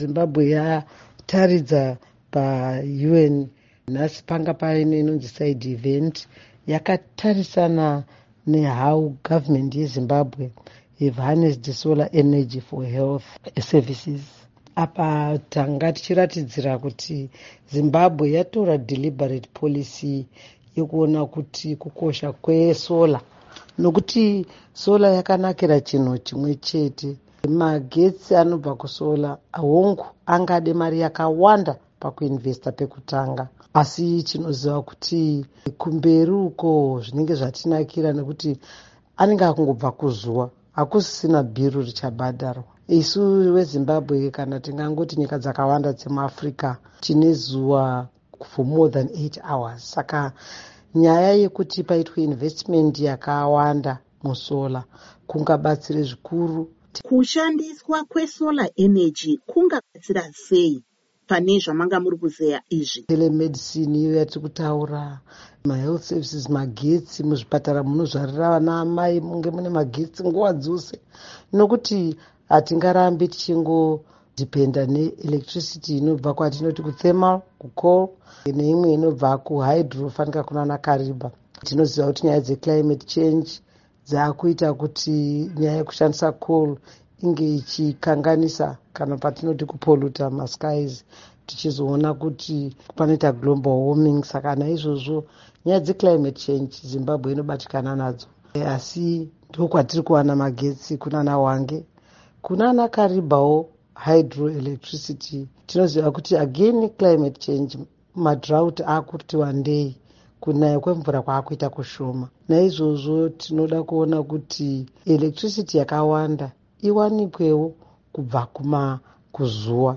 Hurukuro naAmai Monica Mutsvangwa